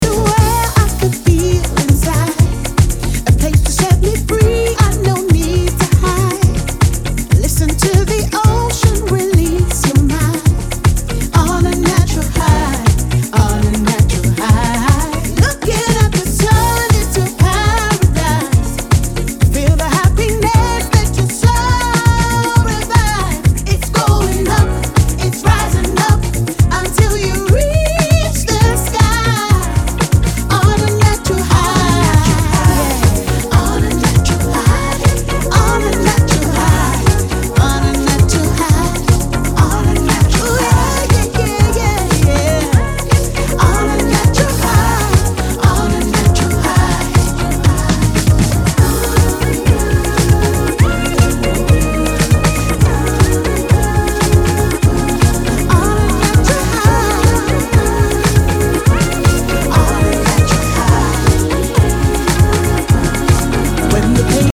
a quality piece of broken beat and heartfelt song